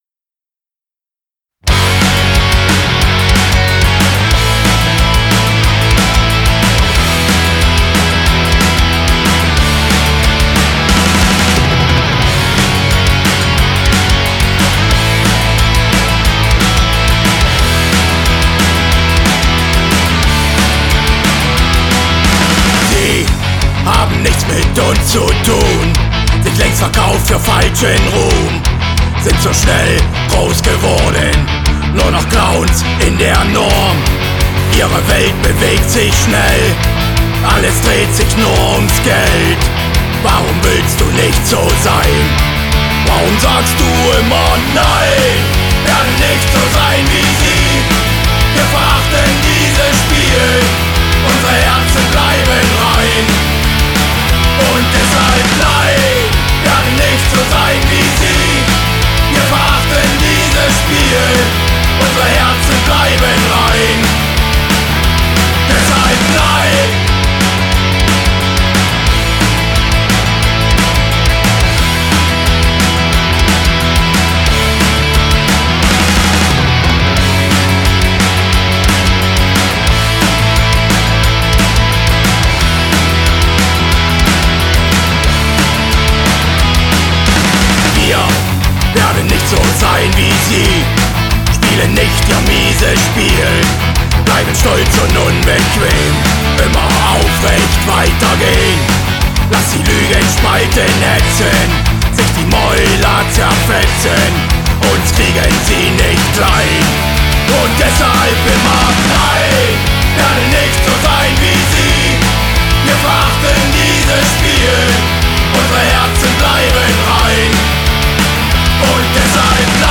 mehr als stimmungsvolles Gitarrespiel